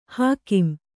♪ hākim